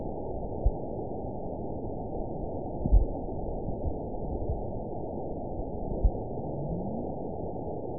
event 917370 date 03/29/23 time 22:34:15 GMT (2 years, 1 month ago) score 9.09 location TSS-AB05 detected by nrw target species NRW annotations +NRW Spectrogram: Frequency (kHz) vs. Time (s) audio not available .wav